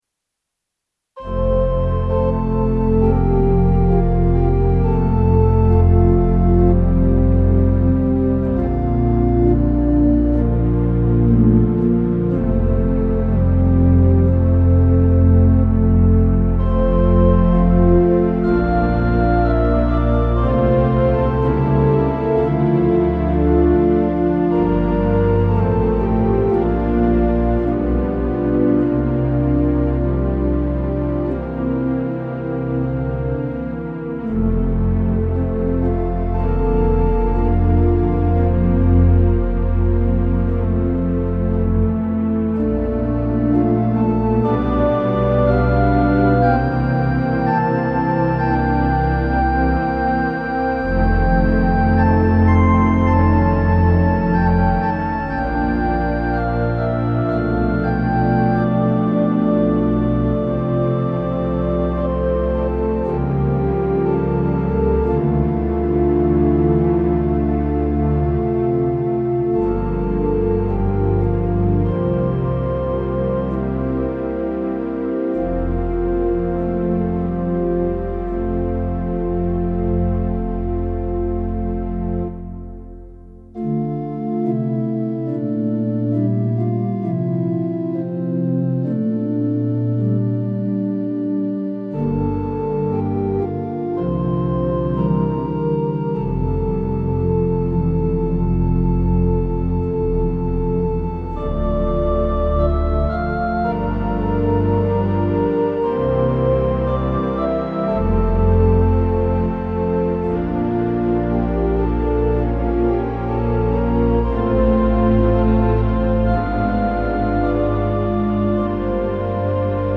St. James Memorial Episcopal Church
1909 Austin Organ, Opus 246